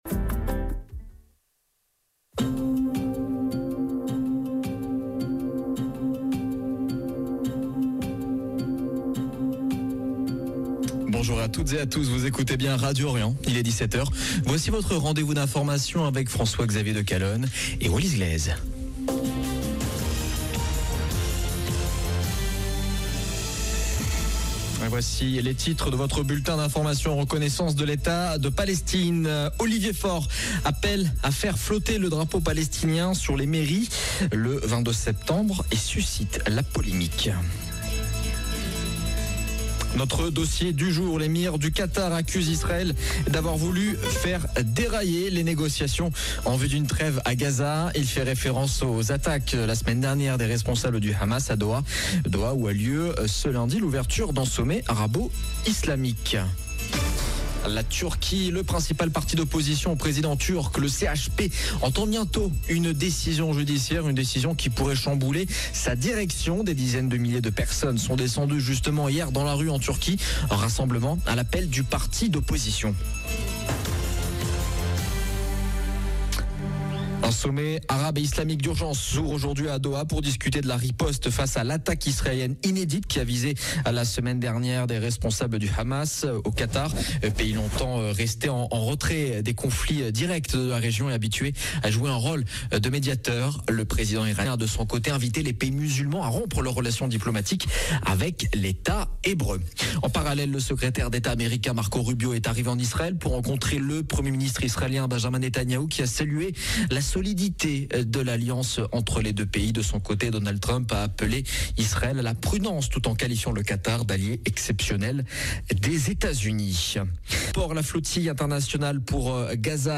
Magazine d'information du 15/09/2025